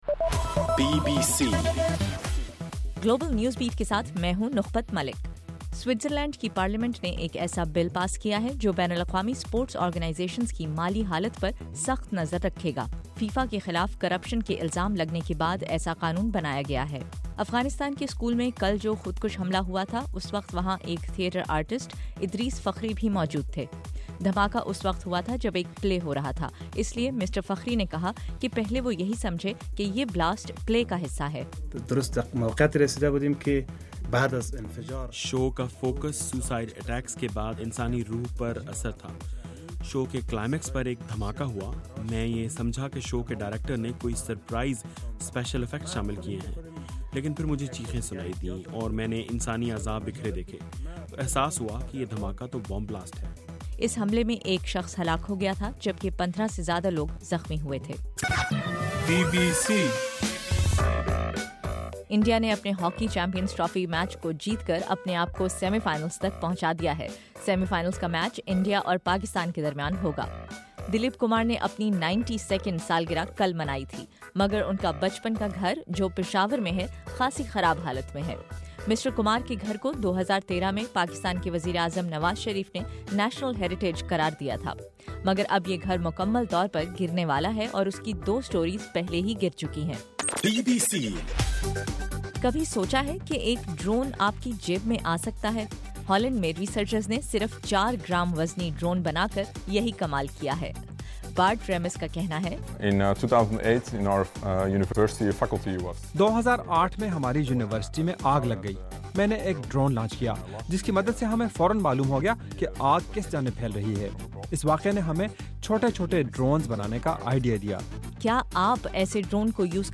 دسمبر 12: رات 9 بجے کا گلوبل نیوز بیٹ بُلیٹن